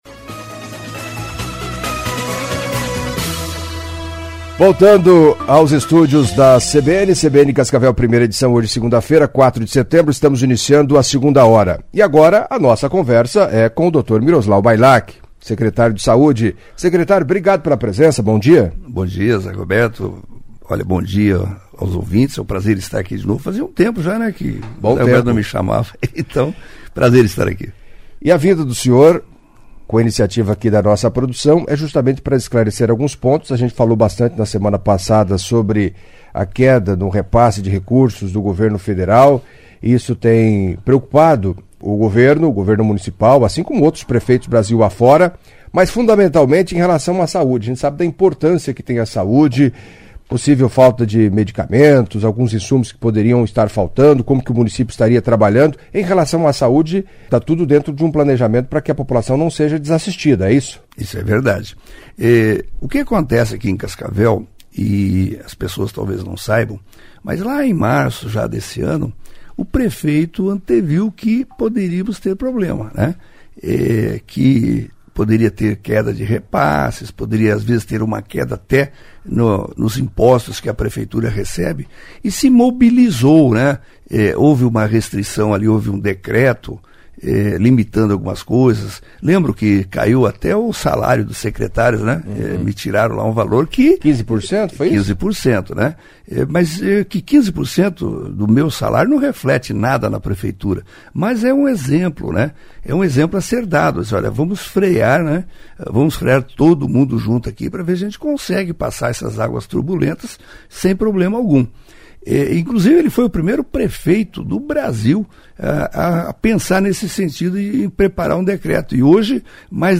Em entrevista à CBN Cascavel nesta segunda-feira (04) Miroslau Bailak, secretário de Saúde, detalhou a situação da pasta e possíveis riscos, a partir da queda no repasse do governo federal aos municípios brasileiros.